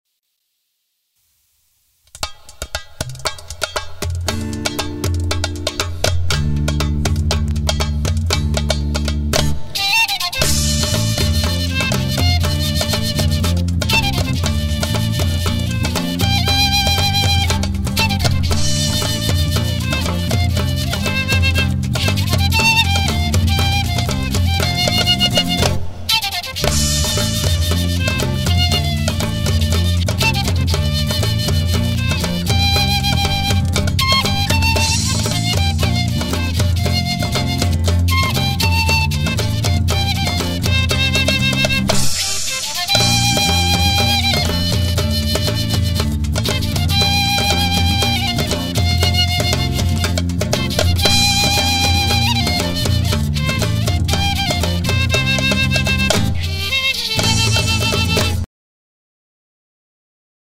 Traditional Japanese folk song